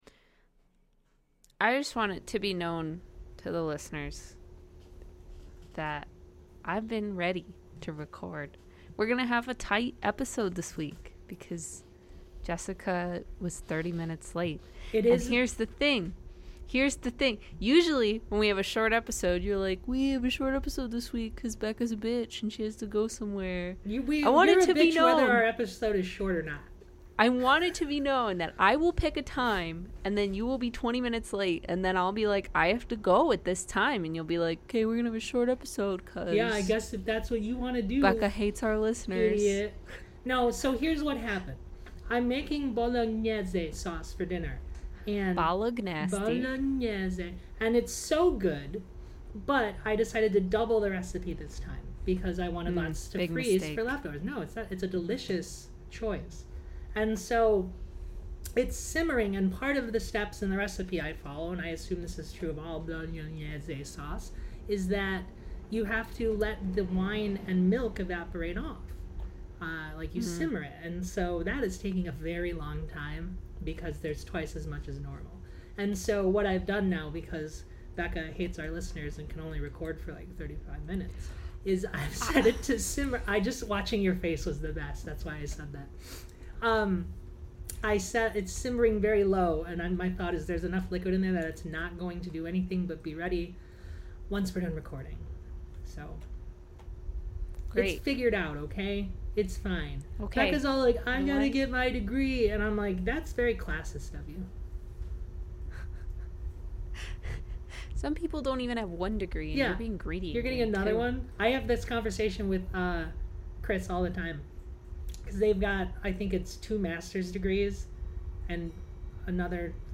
Core Audio has failed us once again, but only for the first half of the episode.